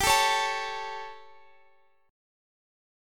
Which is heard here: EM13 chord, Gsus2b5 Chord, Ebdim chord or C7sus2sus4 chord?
Gsus2b5 Chord